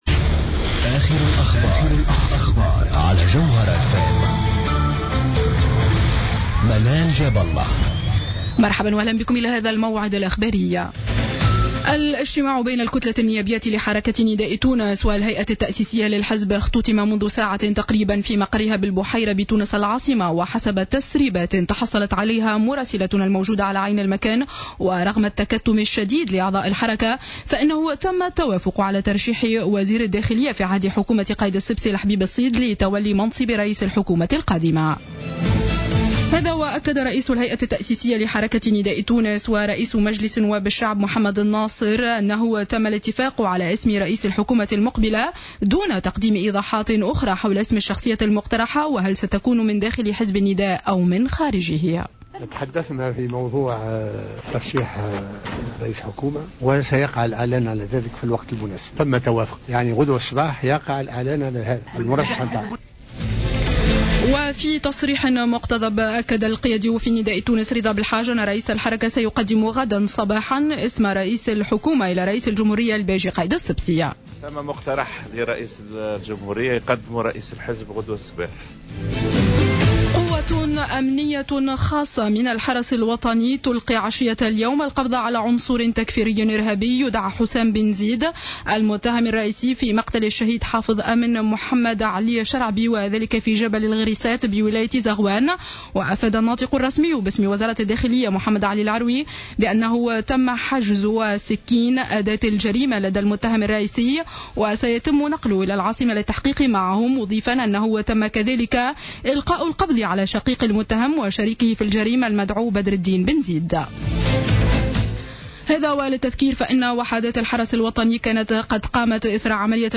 نشرة أخبار السابعة مساء ليوم الأحد 04-01-15